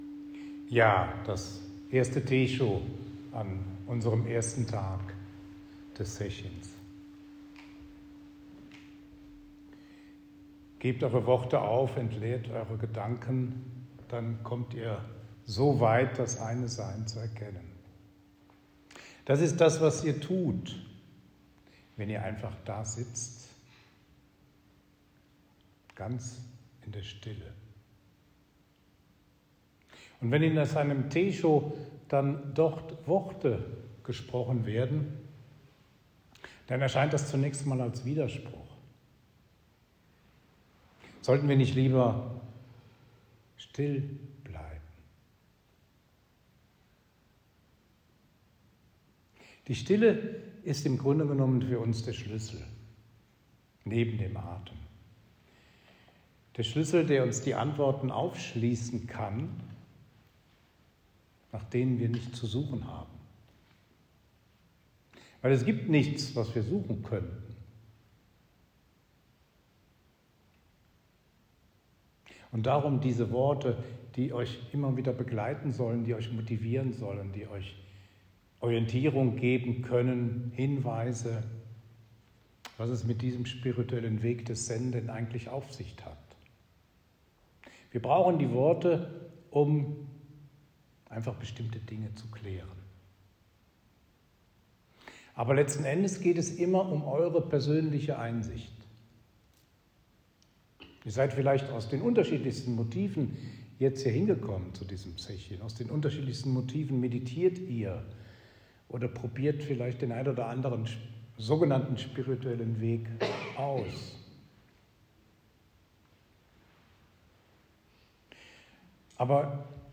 „Teisho 9-23 1.Tag“.
Teisho-9-23-1.Tag_-1.m4a